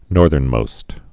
(nôrthərn-mōst)